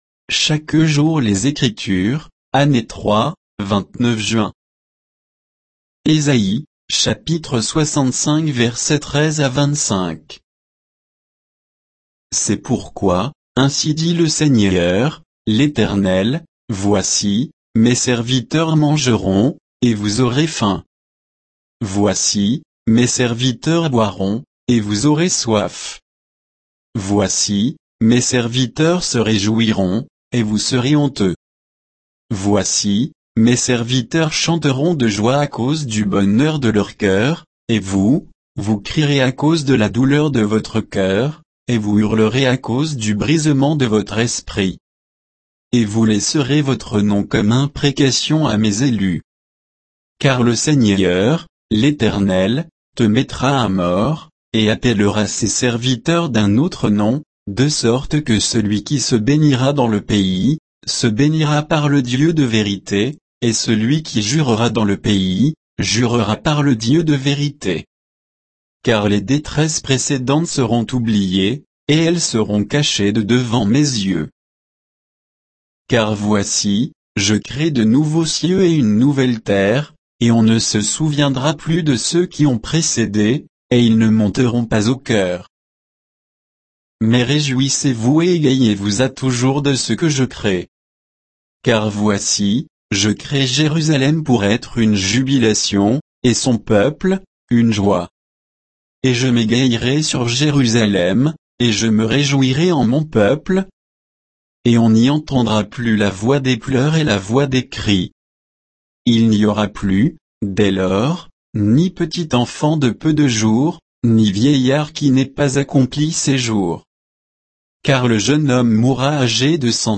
Méditation quoditienne de Chaque jour les Écritures sur Ésaïe 65, 13 à 25